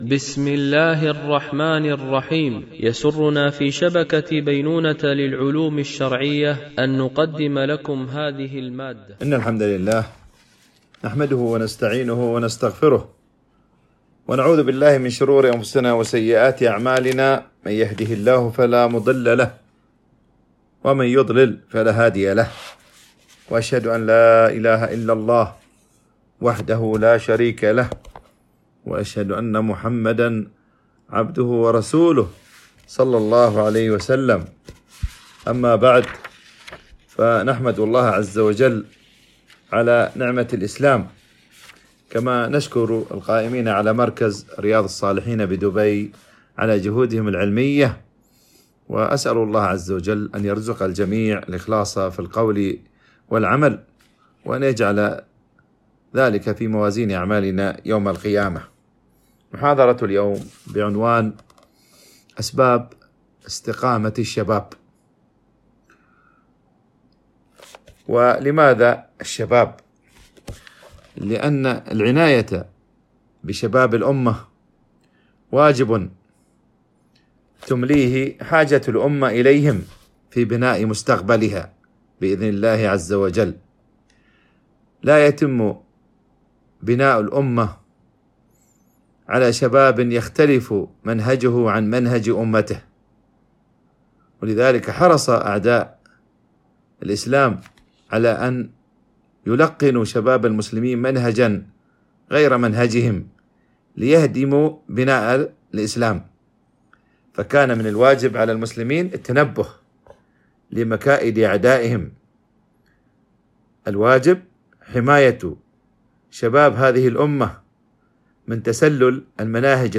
MP3 Mono 44kHz 96Kbps (VBR)